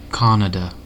Ääntäminen
IPA : /ˈkɑːnədə/